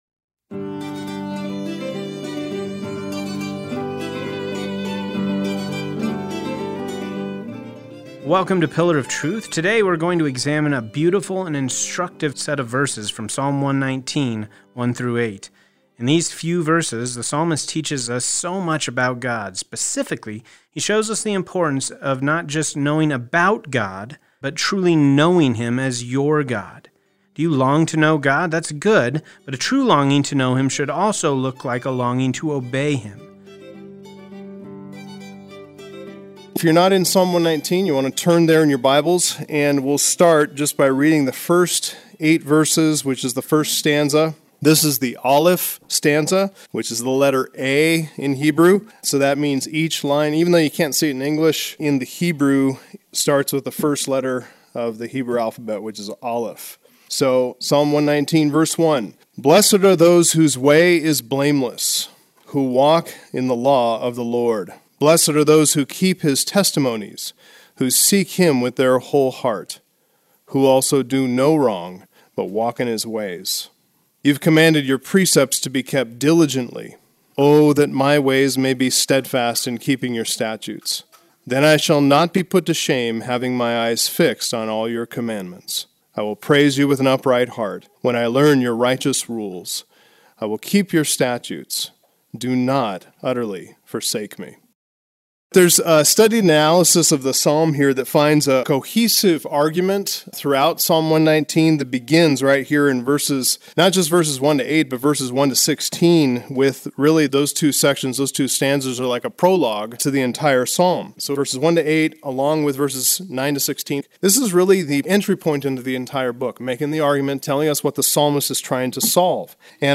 Message Transcription